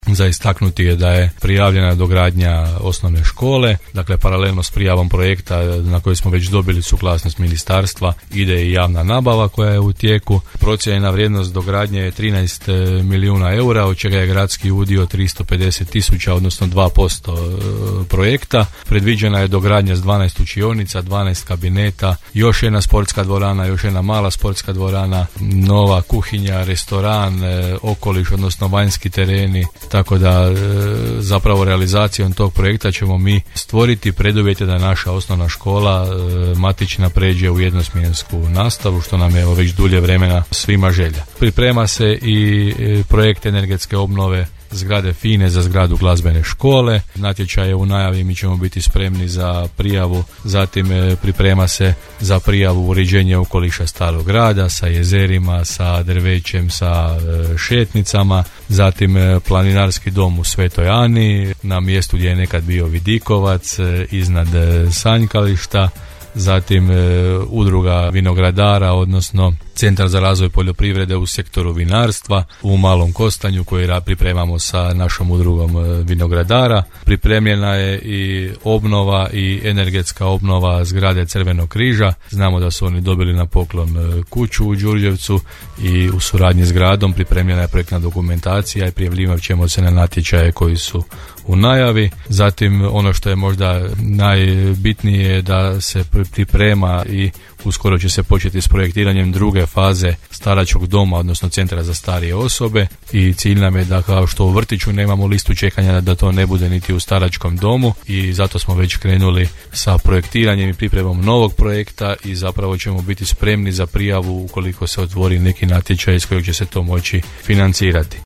Potvrdio je to za Podravski radio gradonačelnik Hrvoje Jnači;